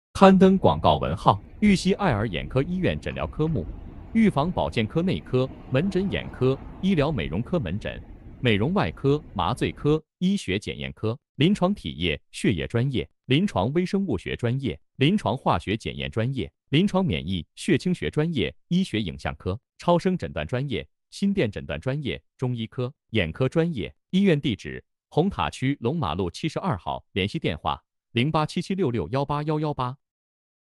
2024.2玉溪爱尔眼科医院医疗广告广播样件.MP3